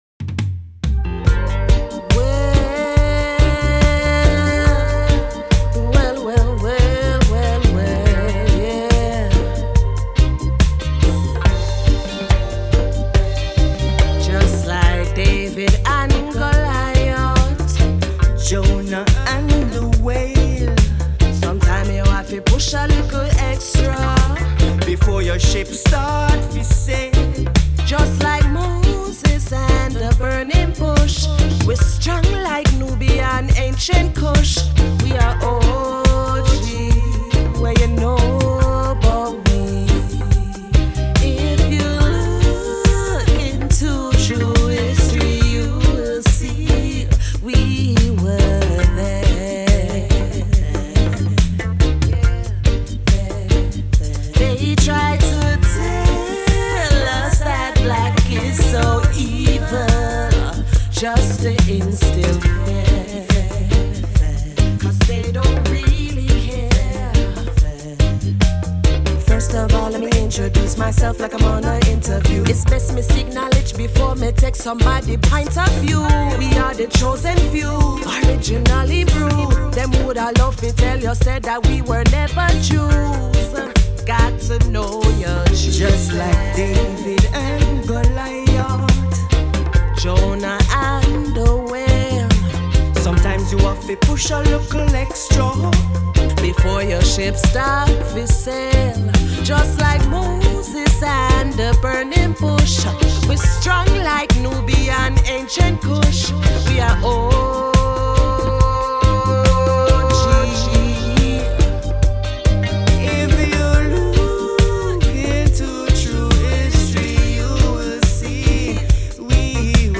traditional Jamaican Dancehall/Reggae and Afrobeat rhythms
My sound is smooth and Rootsy, yet Afrocentric.